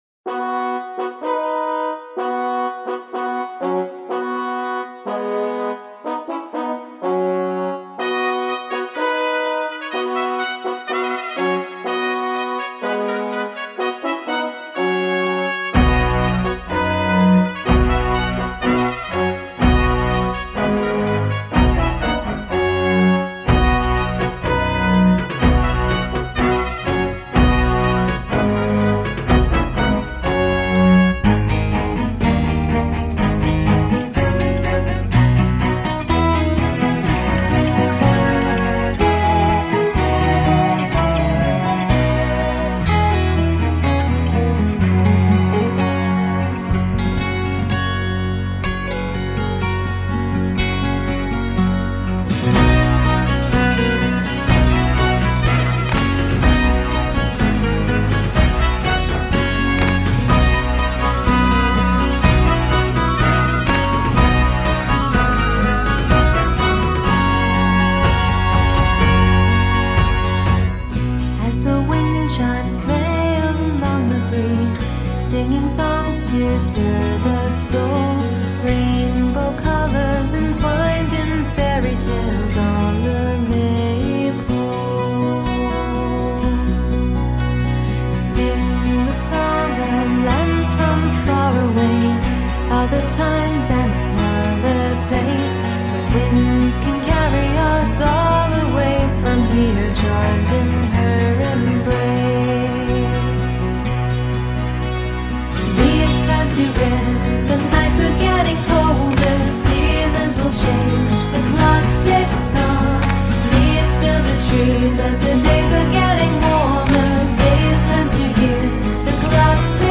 Под сказочную мелодию появляется царь, поет, обходя кровать, в которой спит царевна.